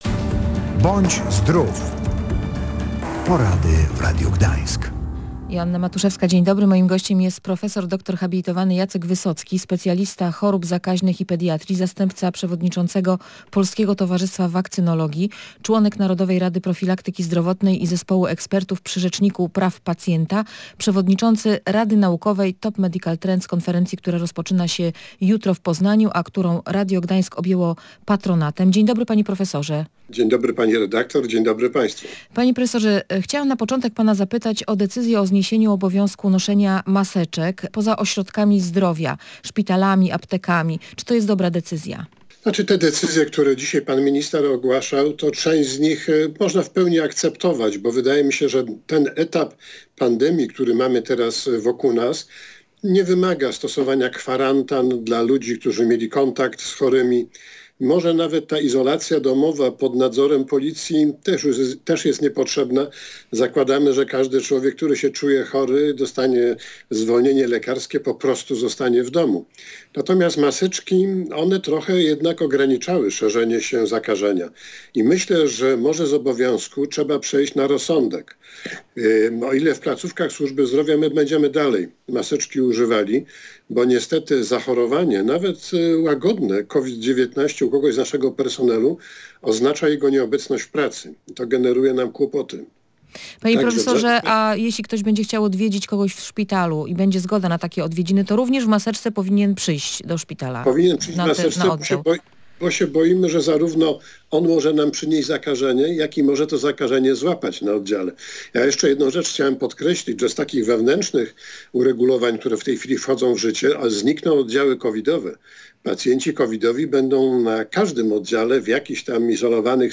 W audycji medycznej tłumaczył, że najpilniejsze szczepienia, jakim należy poddać dzieci, to te przeciwko odrze.